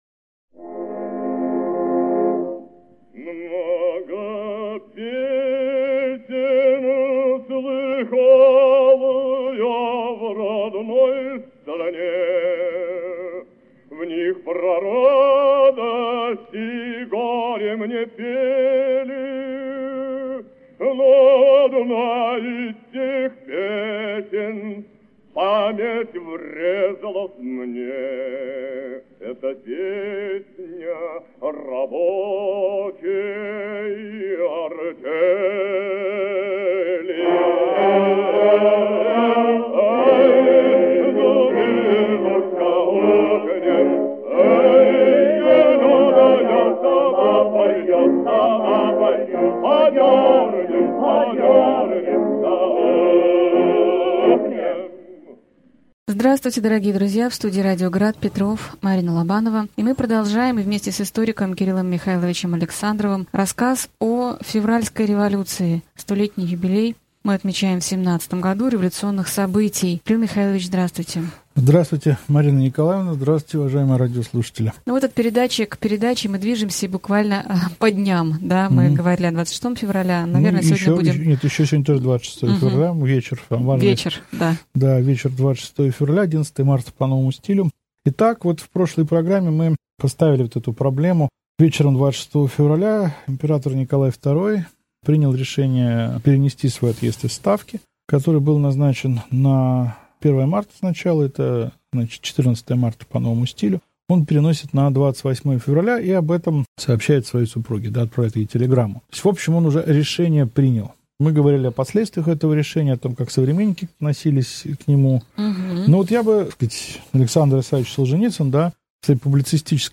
Аудиокнига Февральская революция и отречение Николая II. Лекция 13 | Библиотека аудиокниг